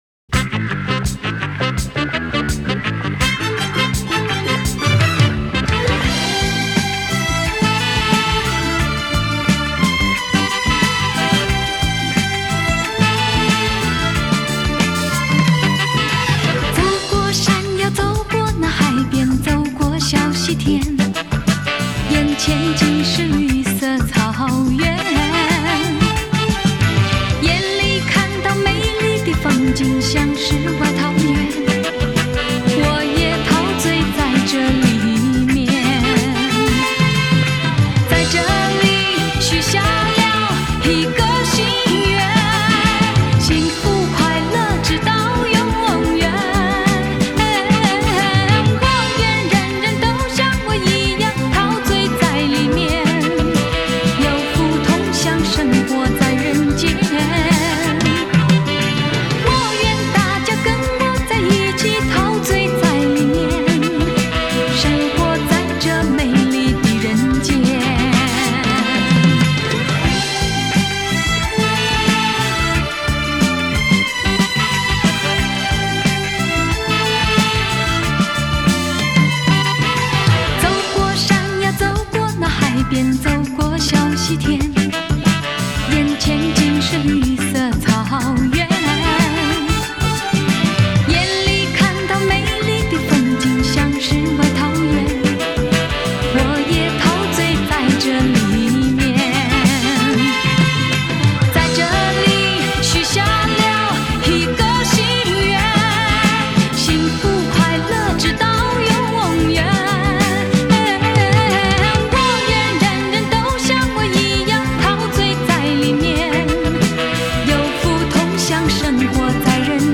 Жанр: Chinese pop / Pop